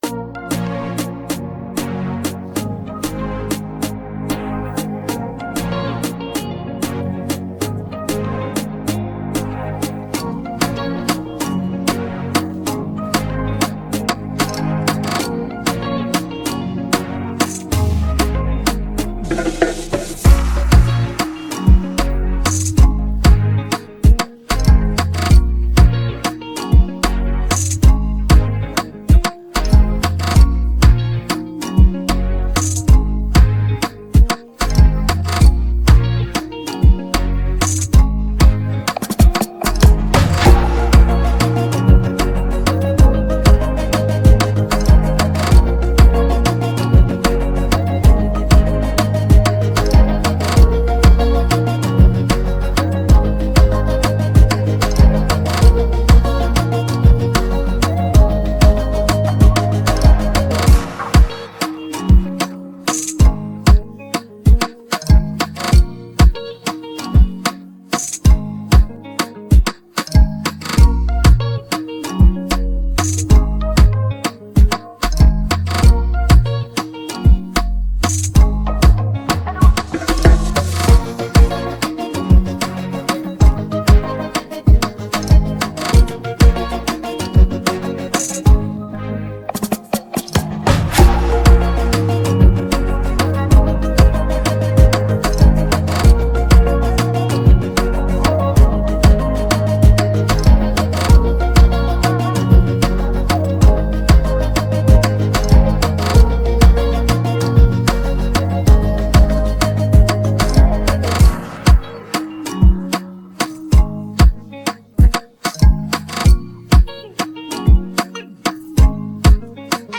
Afro dancehallAfrobeats